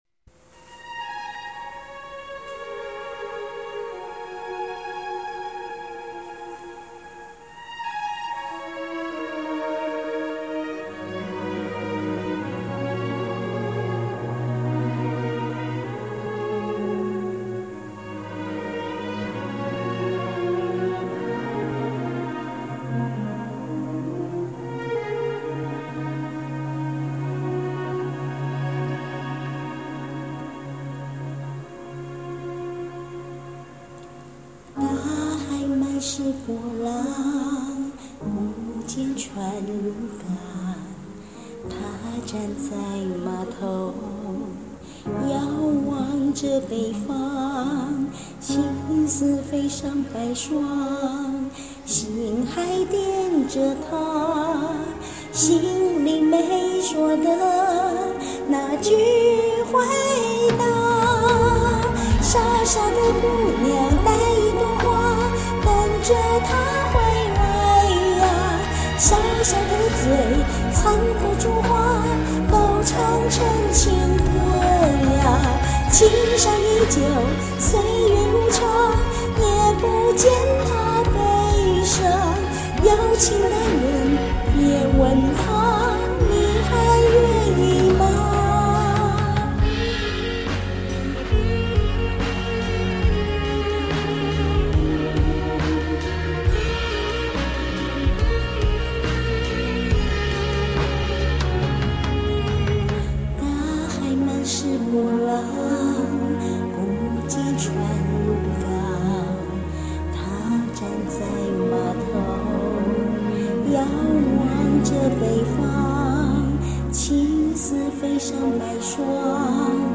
翻唱的